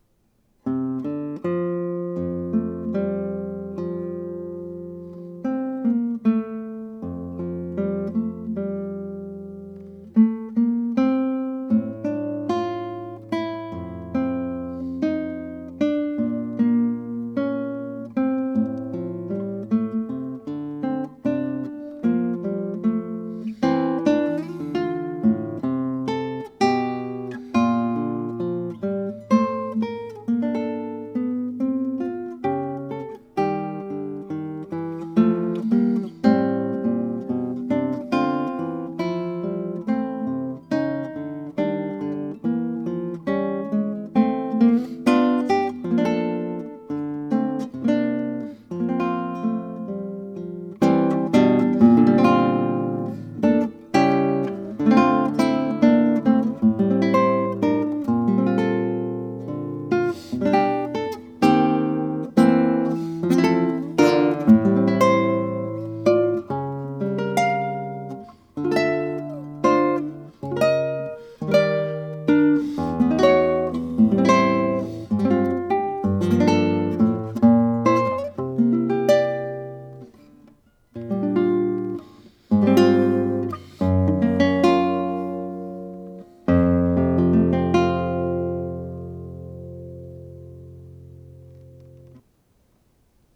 21 short etude-like movements, total length ca. 27 minutes, written in 2013.